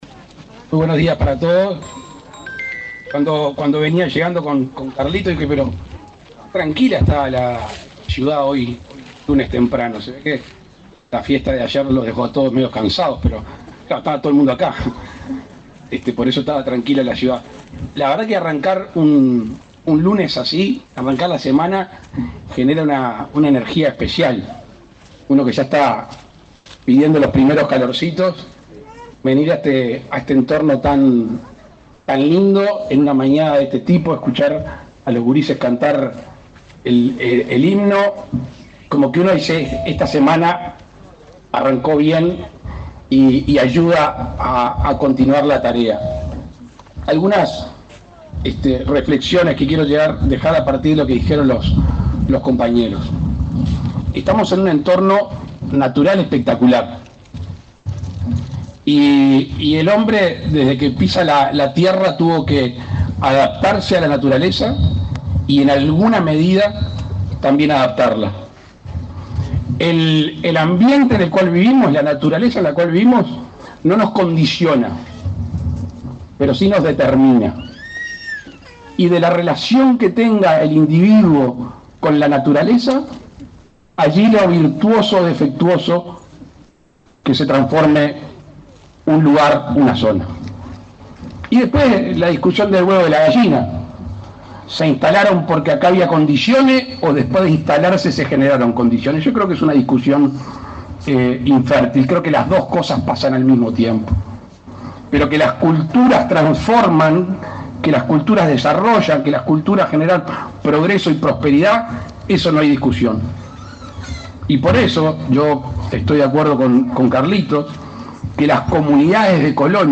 Palabras del presidente Luis Lacalle Pou
El presidente de la República, Luis Lacalle Pou, encabezó, este lunes 26, el acto de inauguración de las obras de dragado del canal sur de la isla